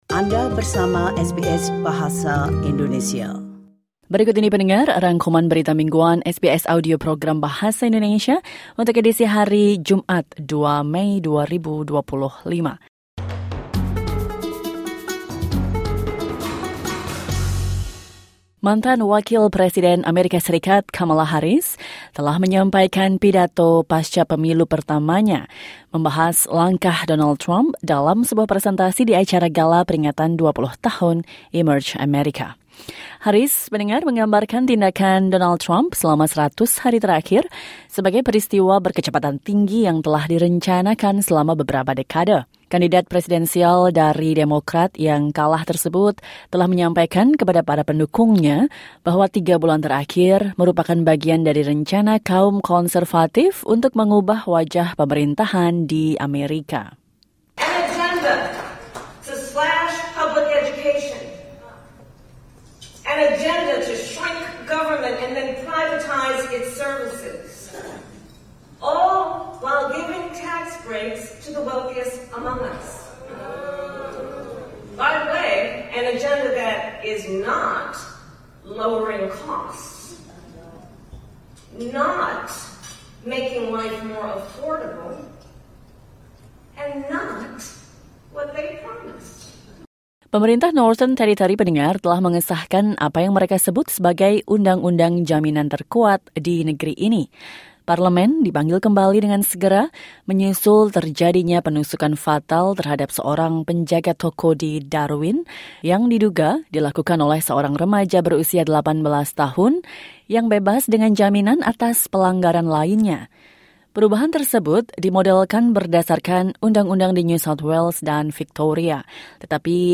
Weekly News Wrap SBS Audio Indonesian Program - 2 May 2025